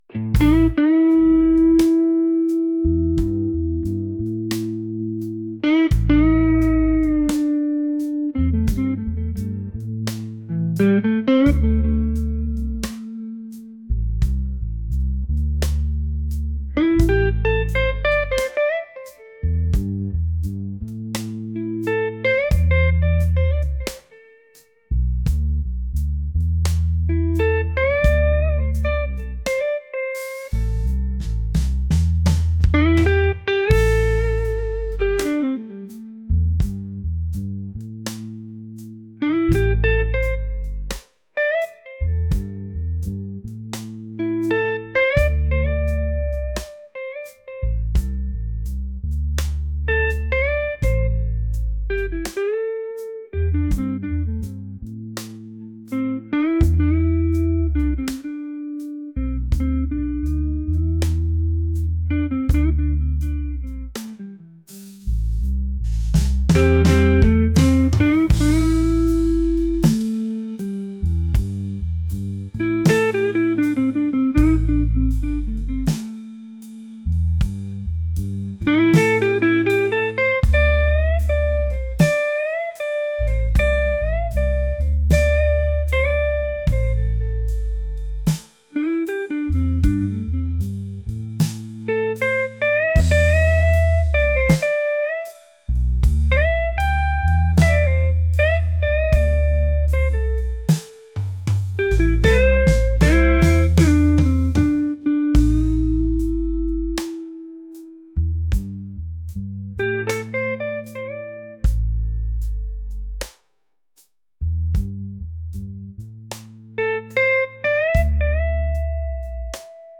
blues | soulful